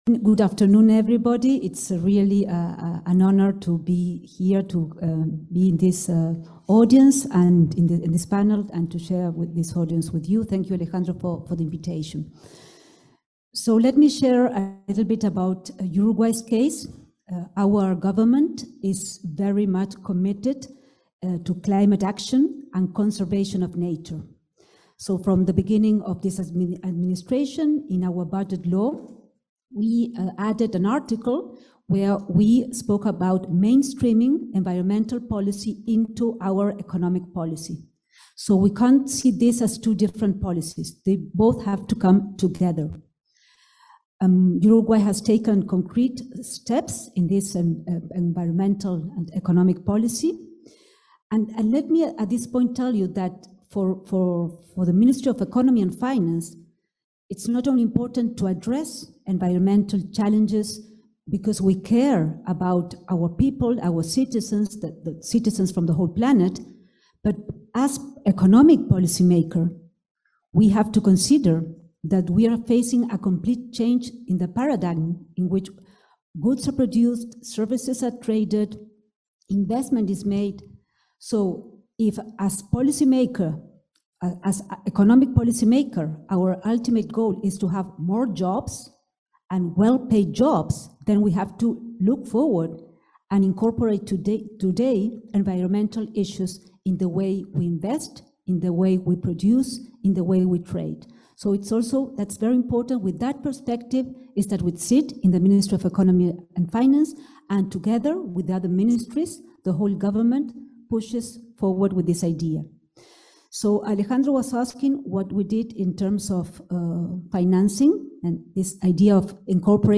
Disertación de la ministra de Economía y Finanzas, Azucena Arbeleche